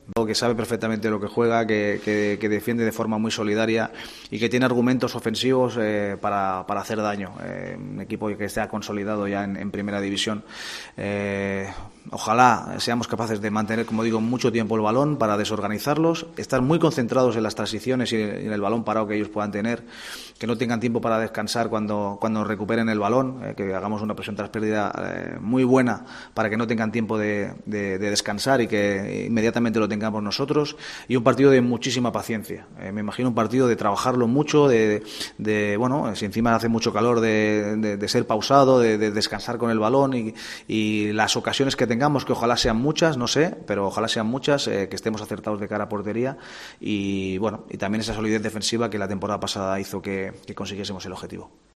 García Pimienta compareció en la primera rueda de prensa de la temporada 2023/24, celebrada en la Ciudad Deportiva.